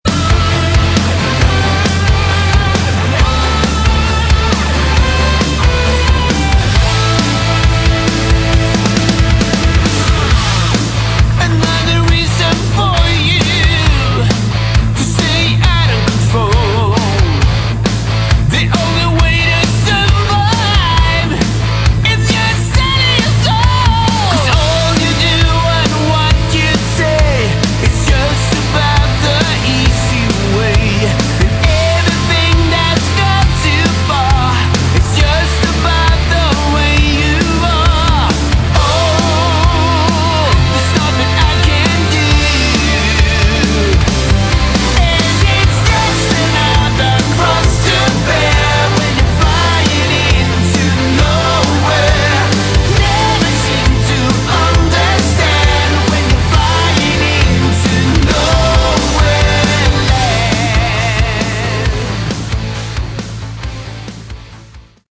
Category: Hard Rock
lead vocals
guitars, vocals
bass, vocals
drums, vocals